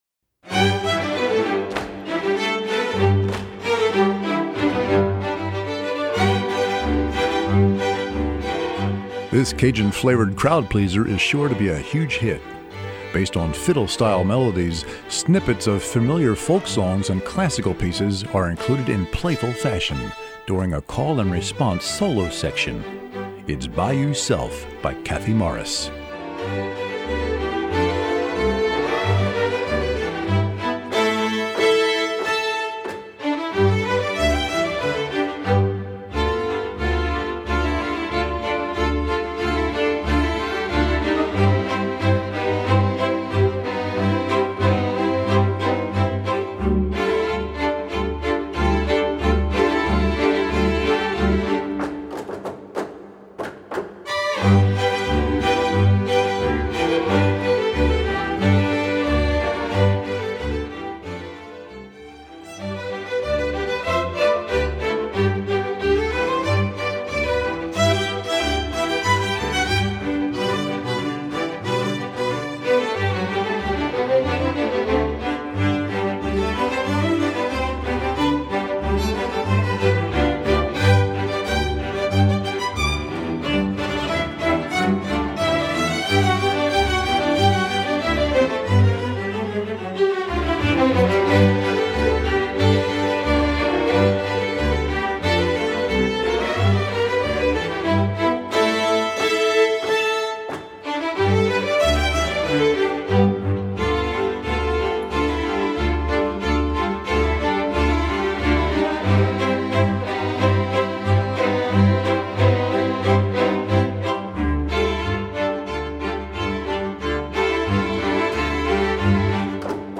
Voicing: Violin and Orch